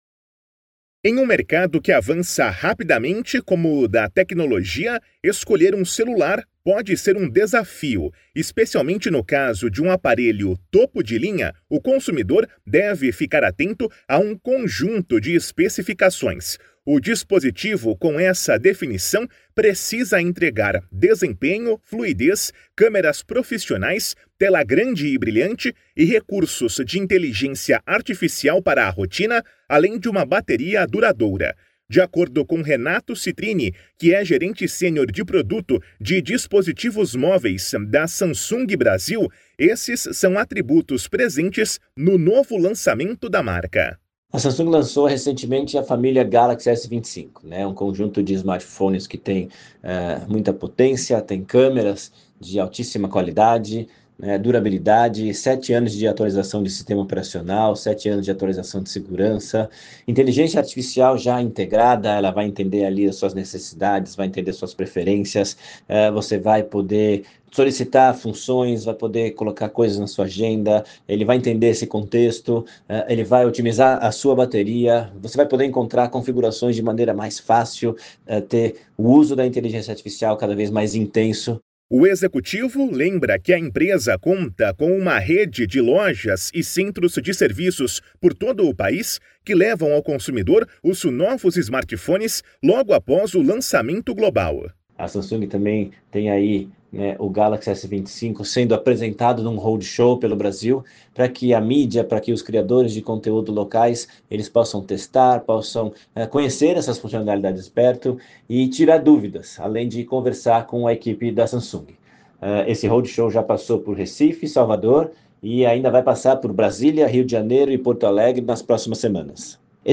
Materiais de Imprensa > Radio Release